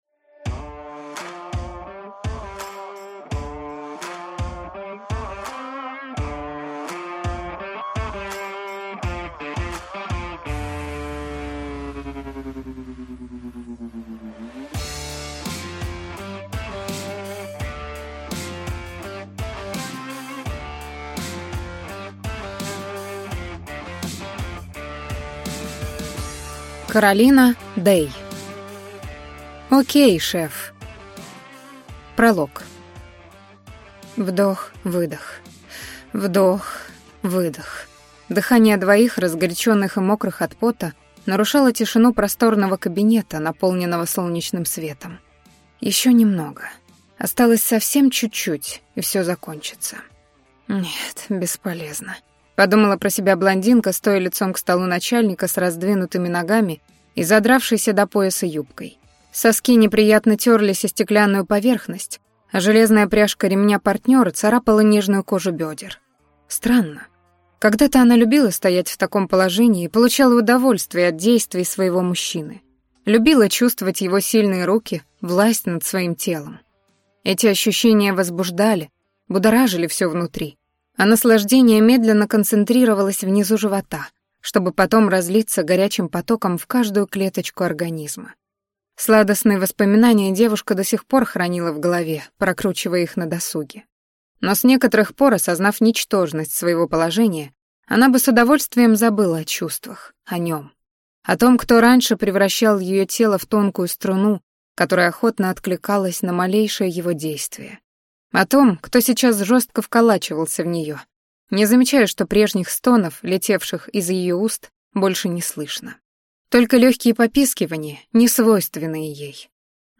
Аудиокнига О'кей, шеф | Библиотека аудиокниг